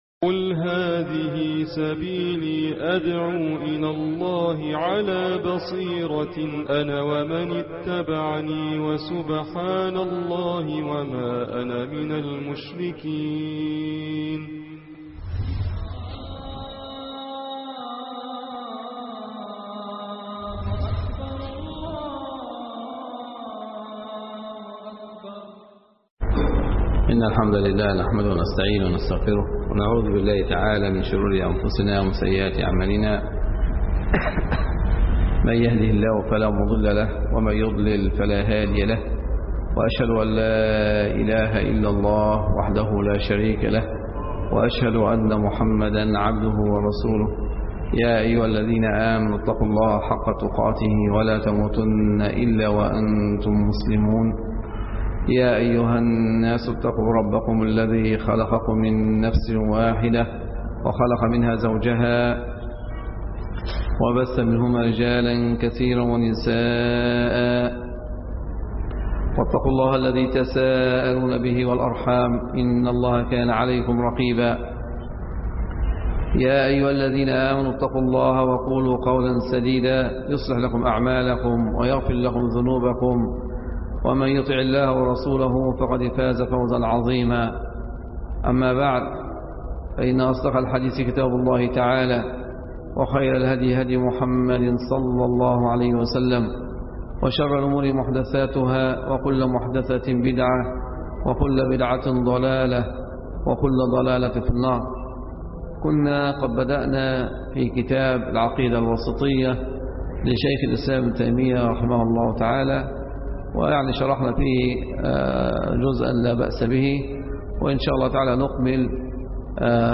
المحاضرة السابعة عشر-شرح العقيدة الوسطية